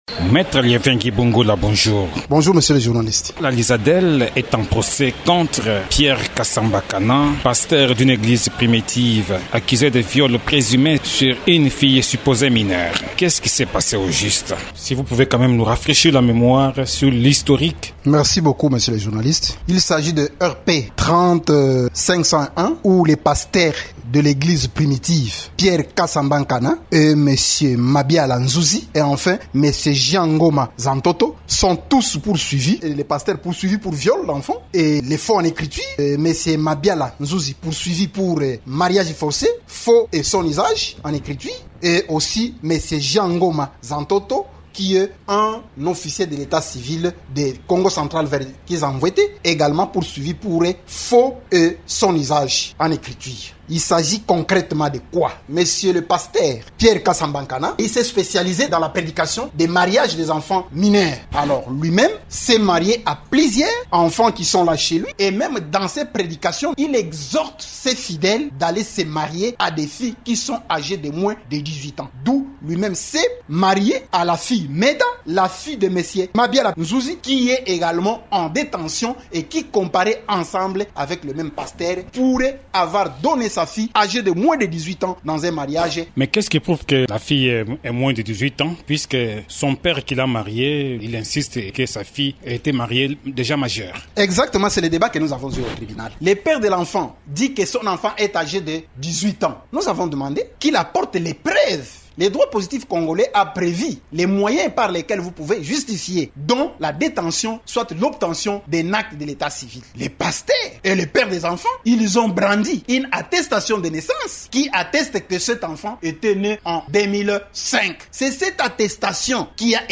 s’entretient